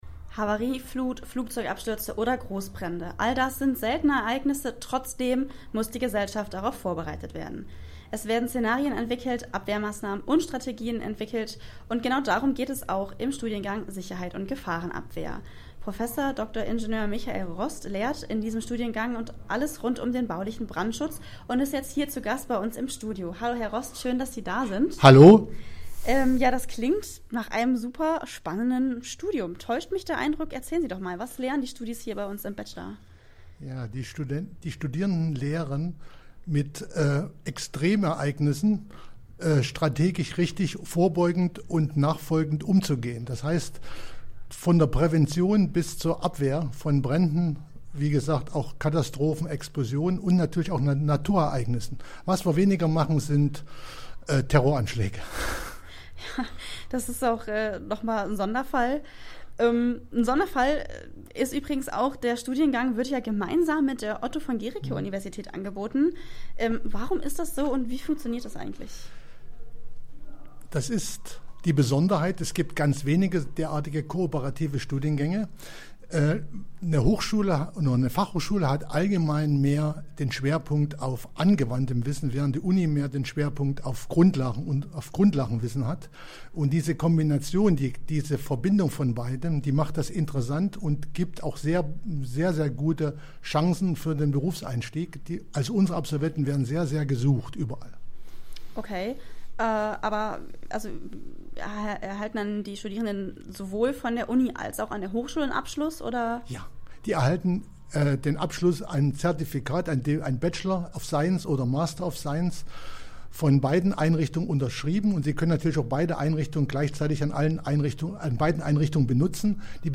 Das Interview vom Campusday 2018